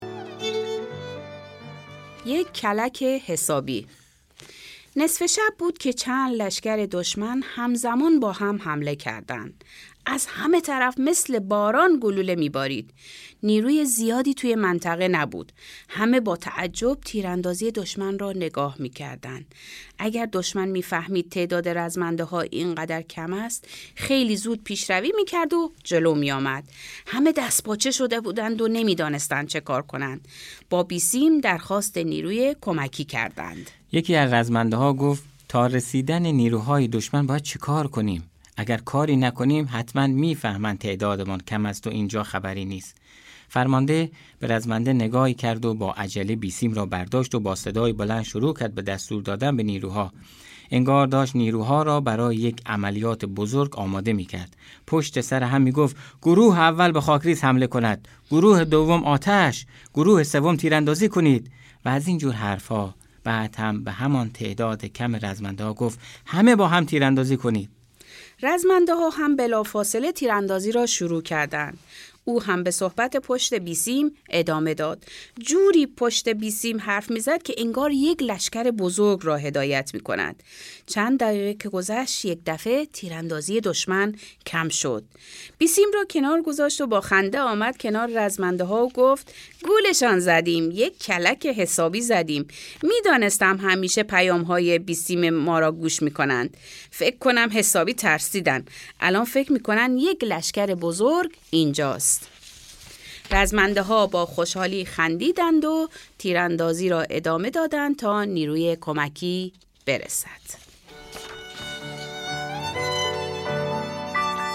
قصه های قهرمان ها«مثل رستم دستان»